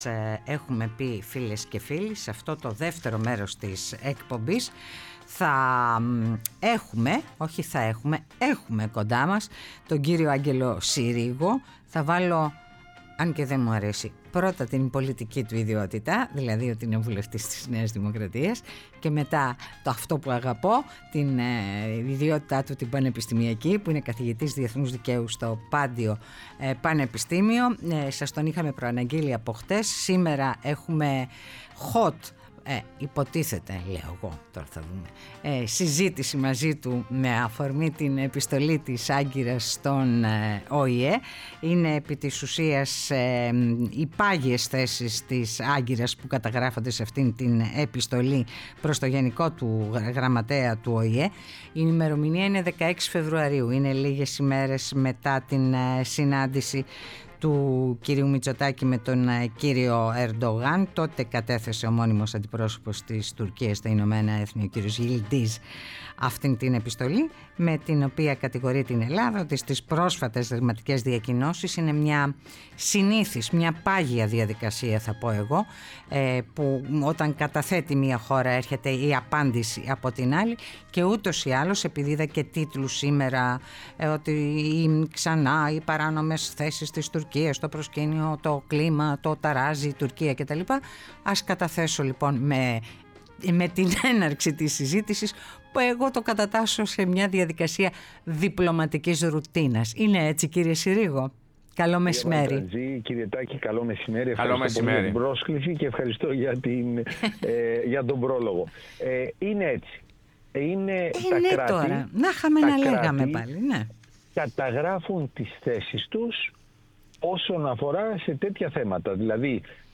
Ο Άγγελος Συρίγος στο ΕΡΤnews Radio 105,8 | 27.02.2026